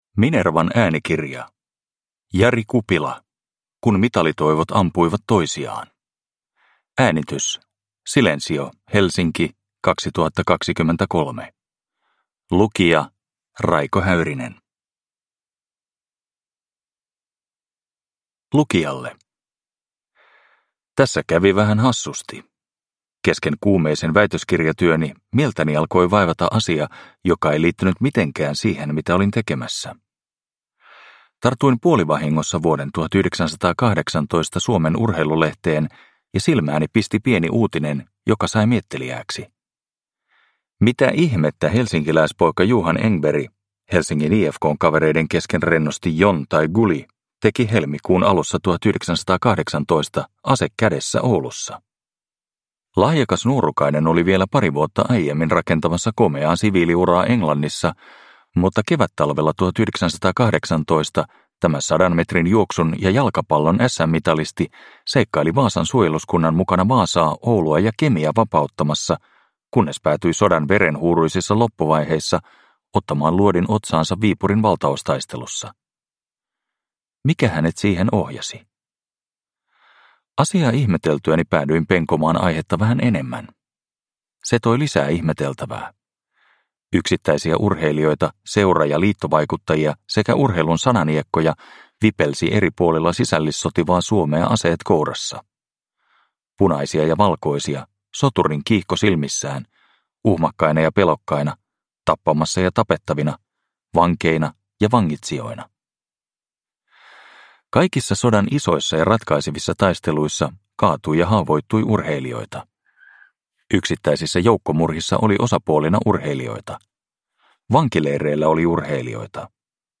Kun mitalitoivot ampuivat toisiaan – Ljudbok – Laddas ner